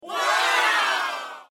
点赞音效礼物弹窗音效
点赞弹窗.MP3